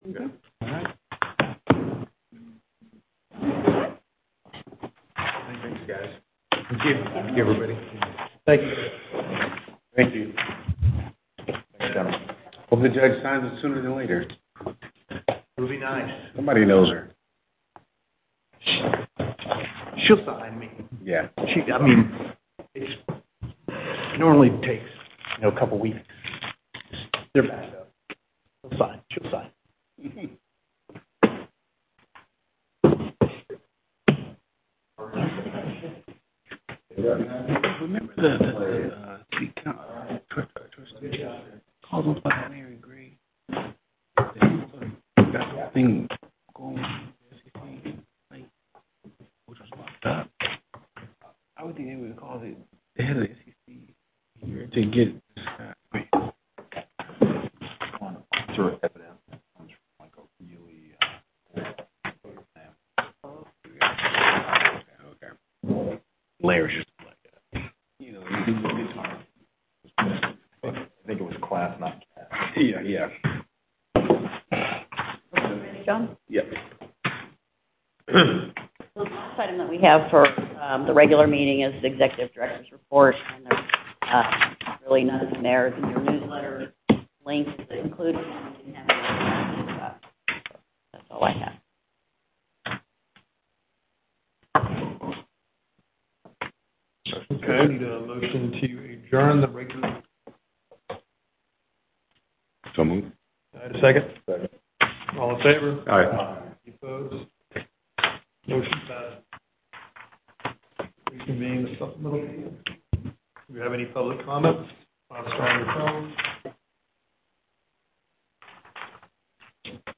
Teleconference Audio 2